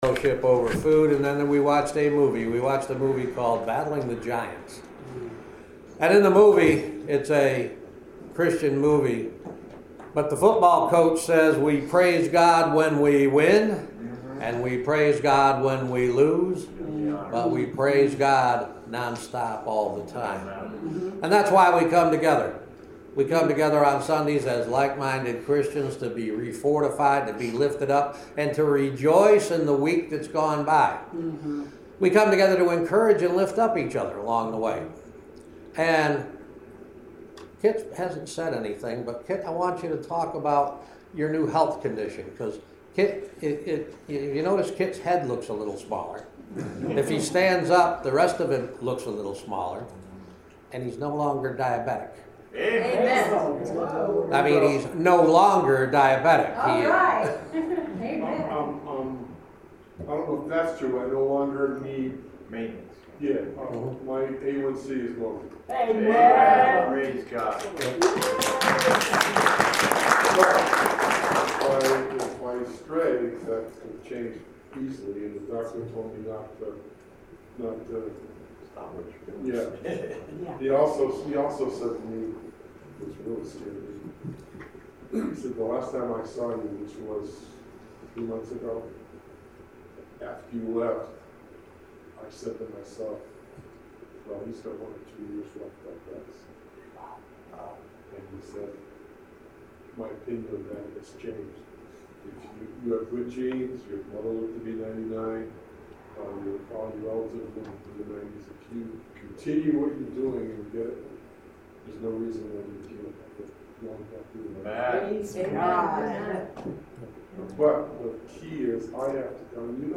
October 2nd, 2016 Service + Communion Podcast
Welcome to the October 2nd, 2016 Service Podcast.
Communion Service
Benediction & Choral Amen